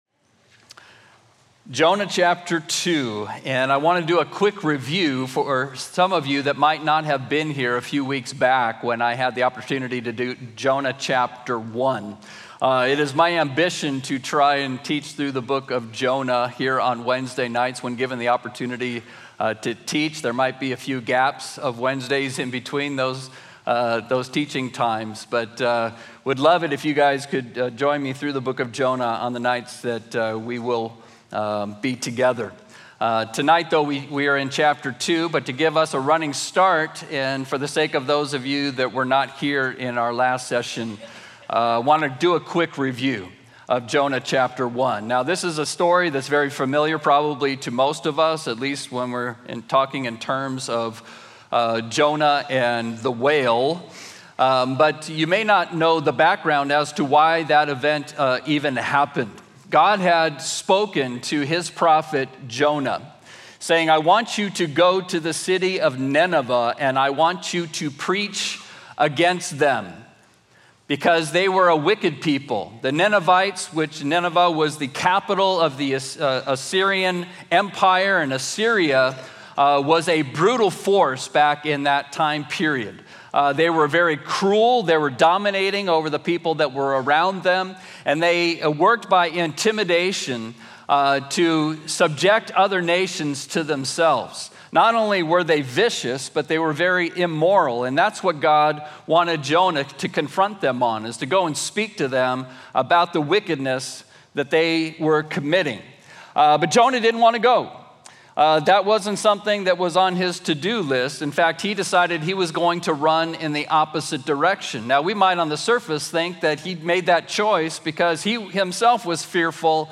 A verse-by-verse sermon through Jonah 2